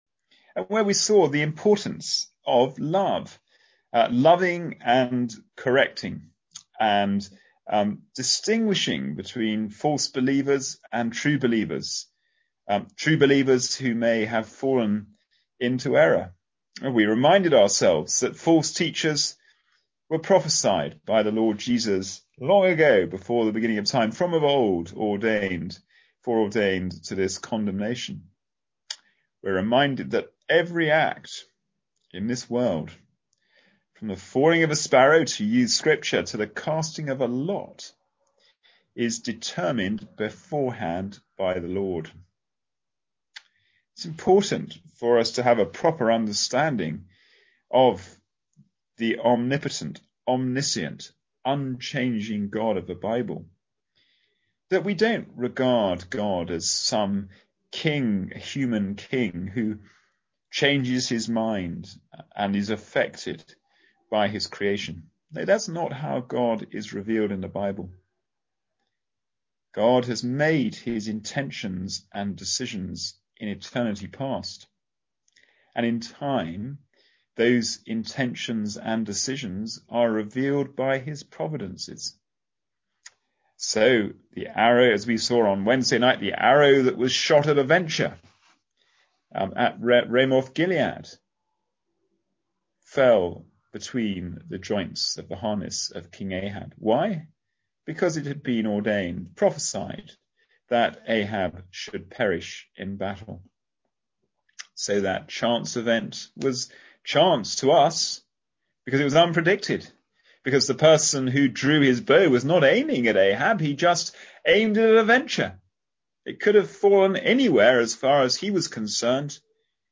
Passage: Jude 1:20-25 Service Type: Sunday Evening Service